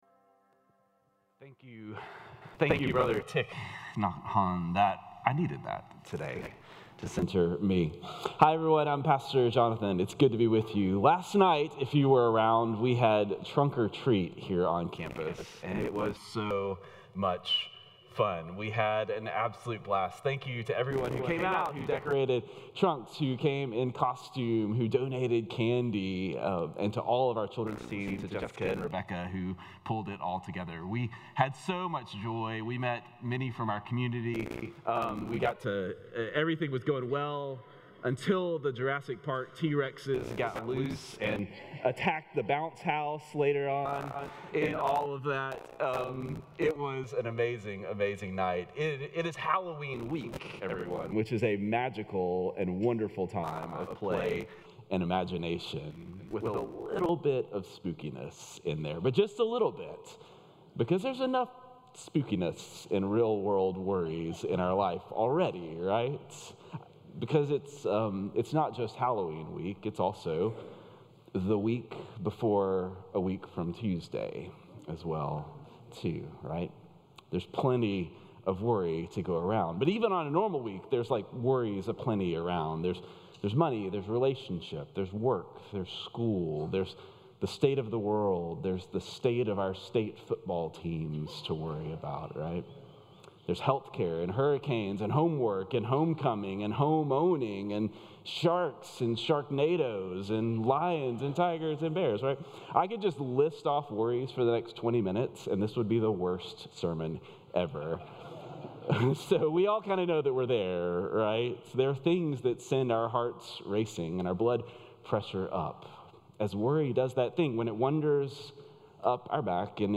Religion